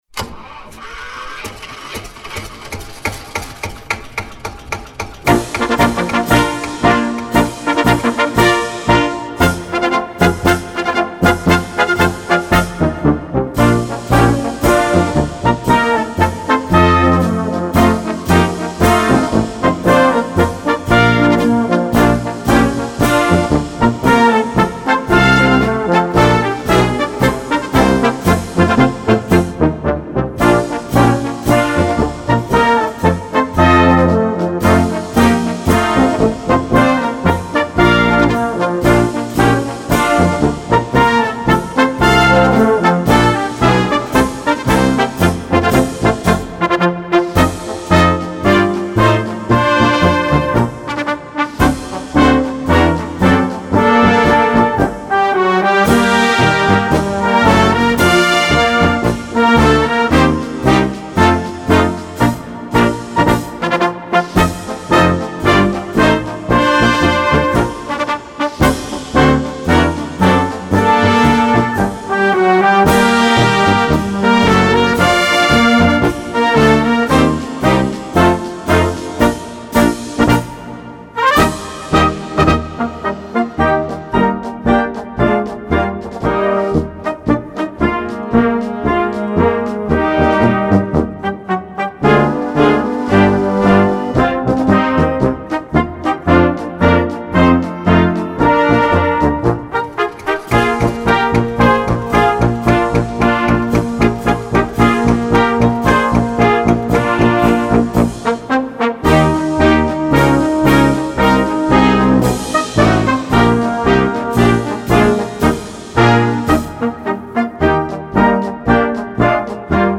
Gattung: Marsch mit Gesangstext im Trio
Besetzung: Blasorchester